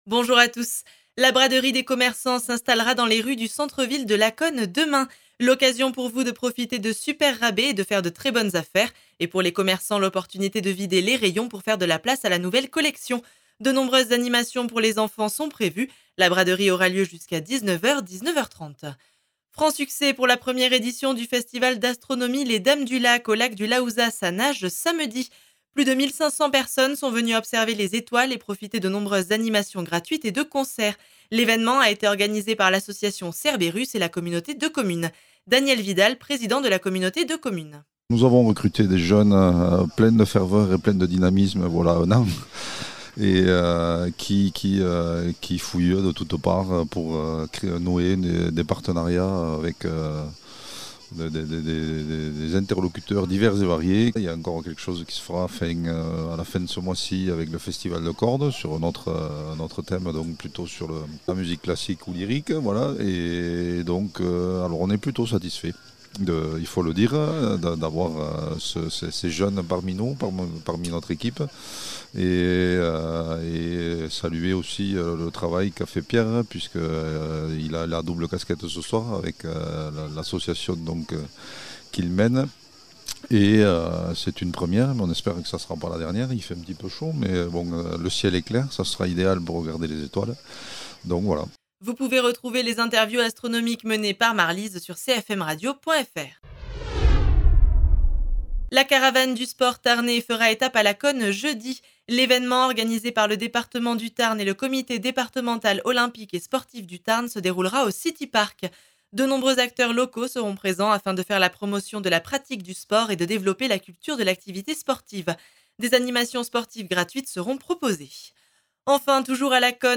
Actualités